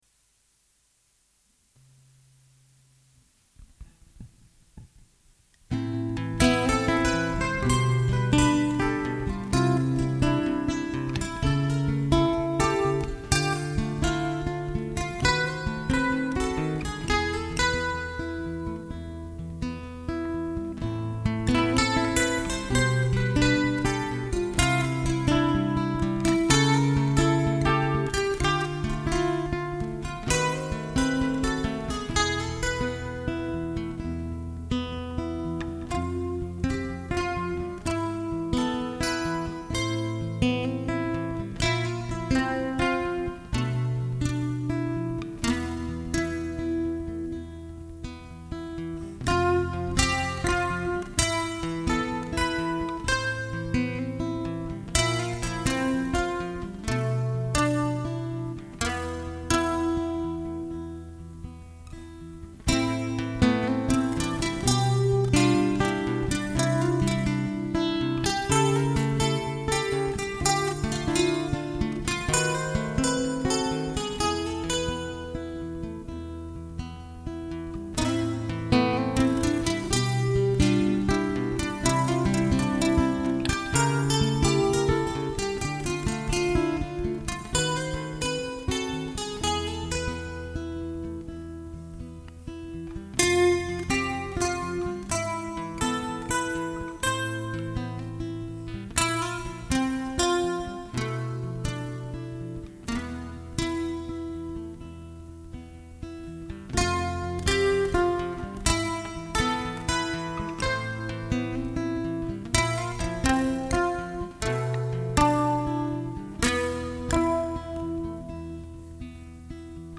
Venez d�couvrir le son de musique acoustique.